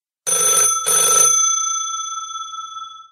Telefono Antiguo sonando (1 timbraso)